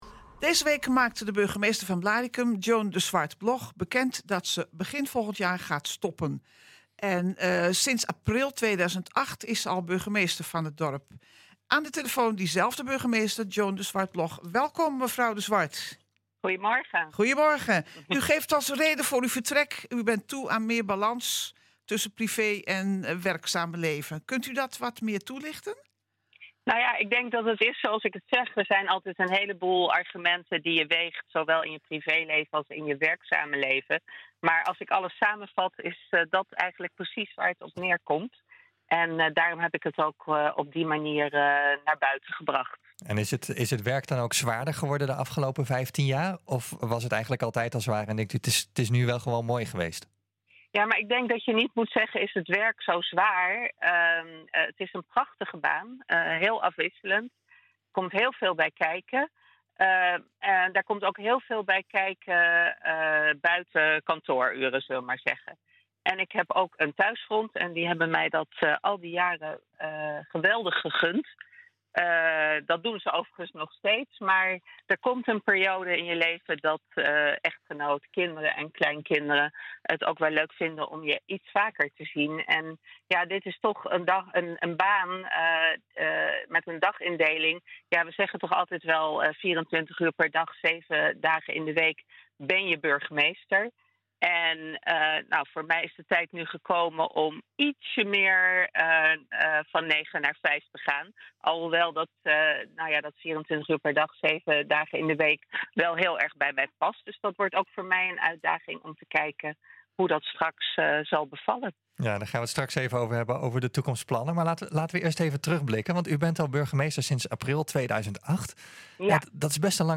En nu aan de telefoon hebben we diezelfde burgemeester van Blaricum, Joan de Zwart-Bloch.